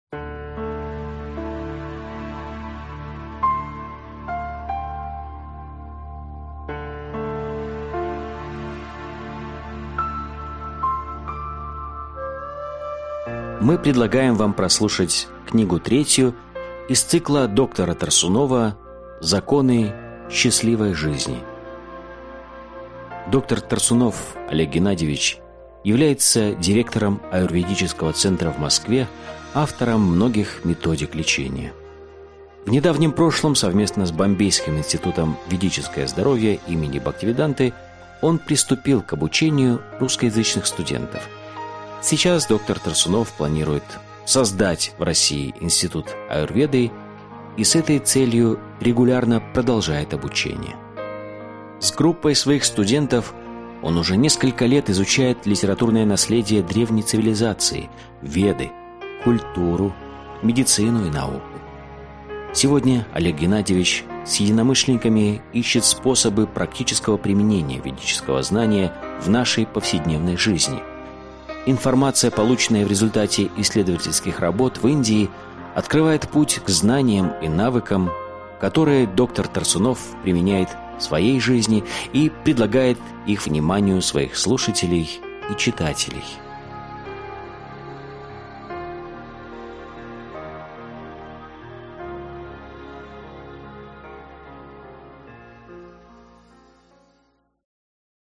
Аудиокнига Законы счастливой жизни. Том 3 | Библиотека аудиокниг